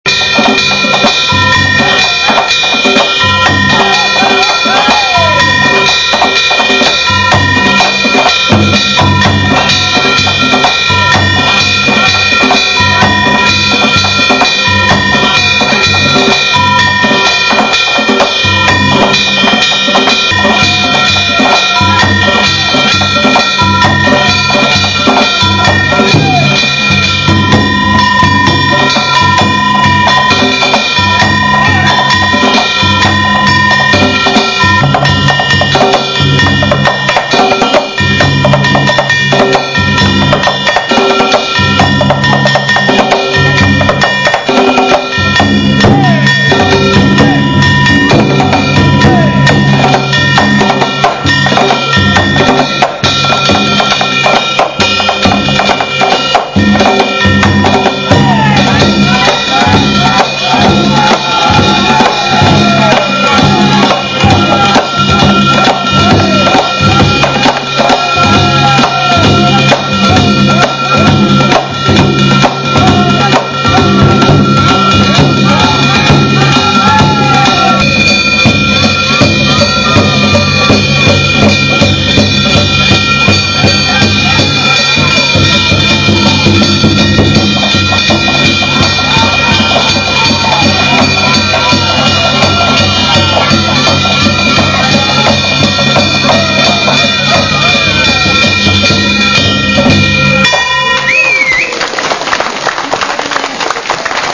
ですが(-_-;)）グッドタイミングで野里のお囃子を聴くことができました。
野里西之町の皆さんによるお囃子と野里の皆さんの踊りです